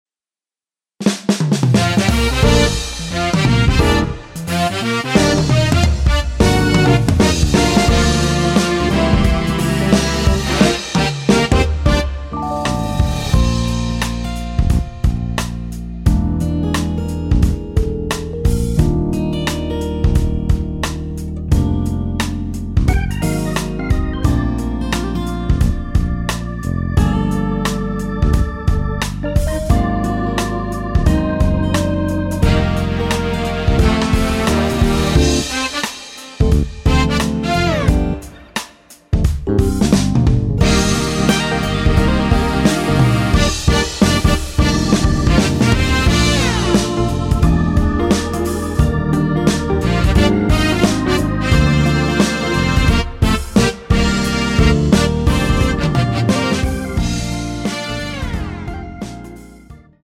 원곡이 많이 높아서 대부분의 남성분이 부르실수 있도록 제작한 키의 MR 입니다.
Bb
앞부분30초, 뒷부분30초씩 편집해서 올려 드리고 있습니다.
중간에 음이 끈어지고 다시 나오는 이유는